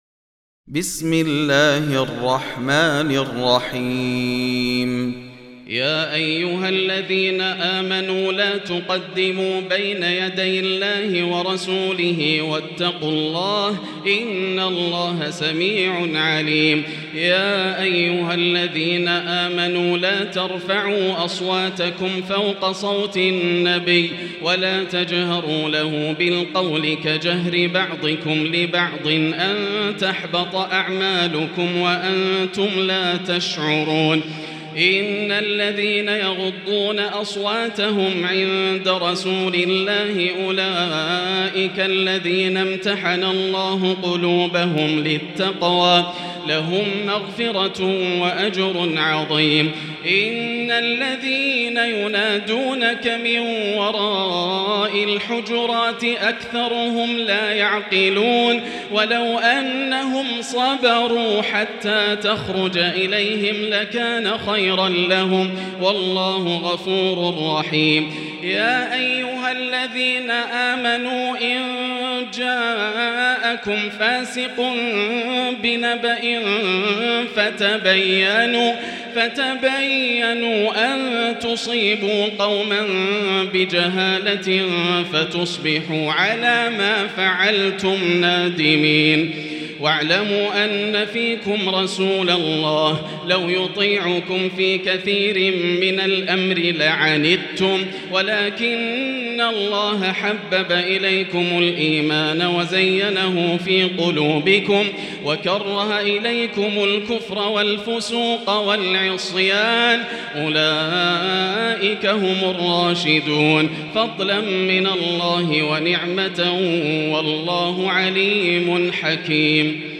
المكان: المسجد الحرام الشيخ: معالي الشيخ أ.د. بندر بليلة معالي الشيخ أ.د. بندر بليلة الحجرات